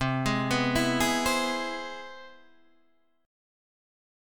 C+M7 chord